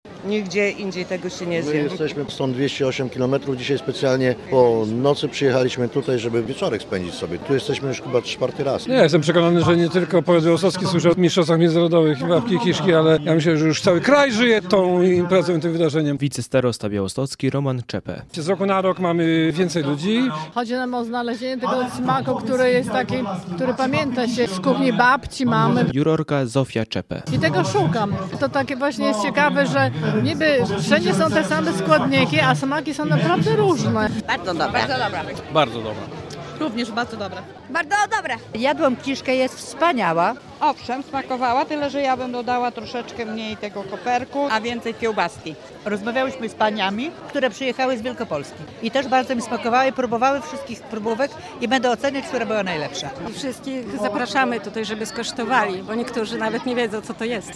Światowe Mistrzostwa w Pieczeniu Babki i Kiszki Ziemniaczanej - relacja